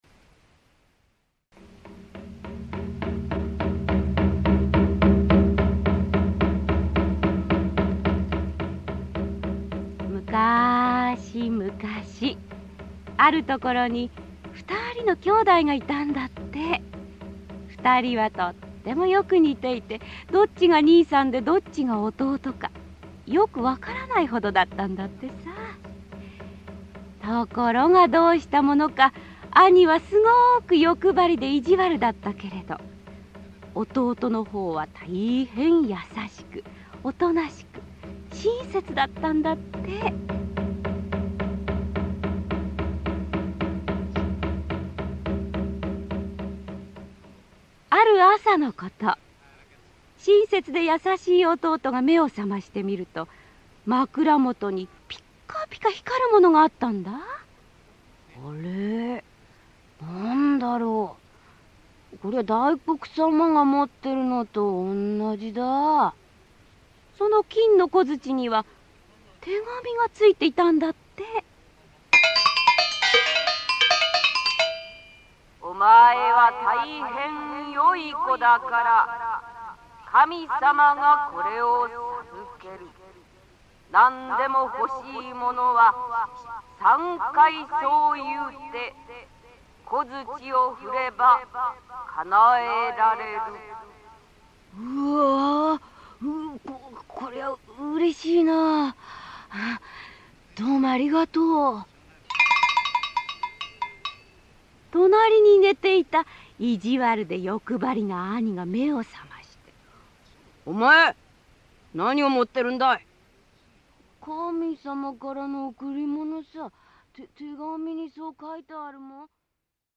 [オーディオブック] 塩ふき小づち